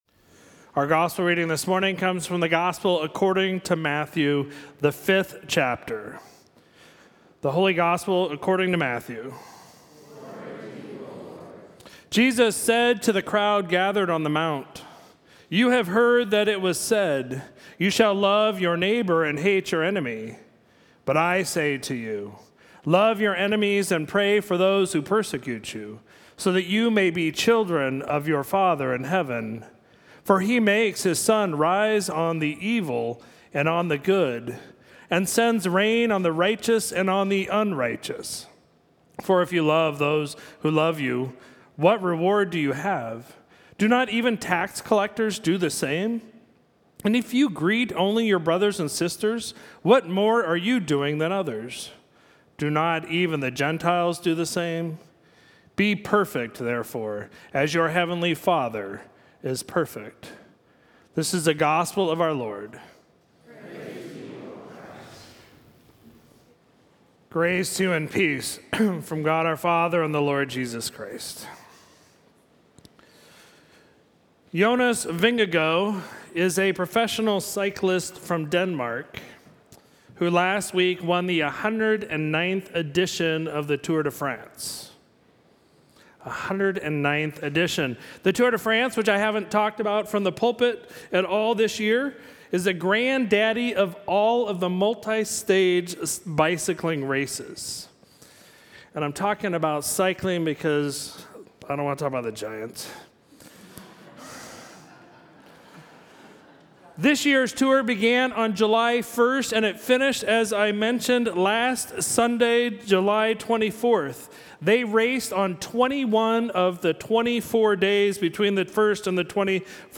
Sermon for Sunday, July 31, 2022